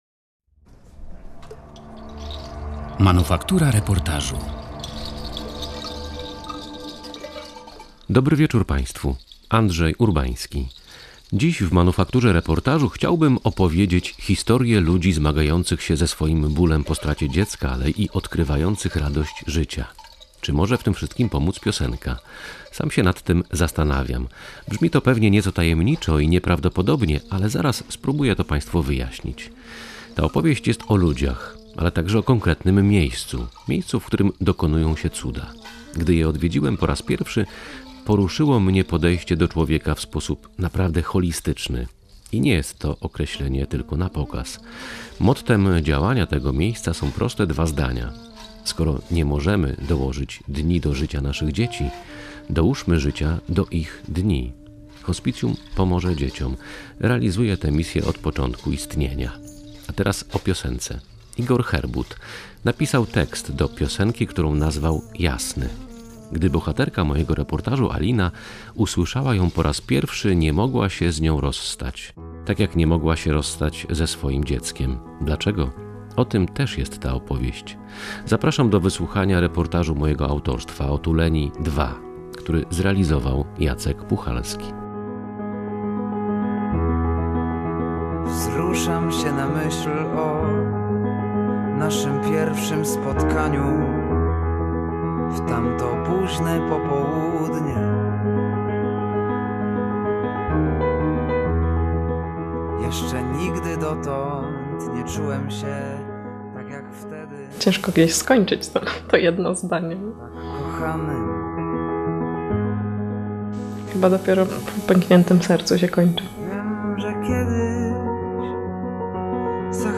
Jak przekuć wielki ból w radość życia? O tym w reportażu „Otuleni II”